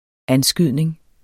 Udtale [ ˈanˌsgyðˀneŋ ]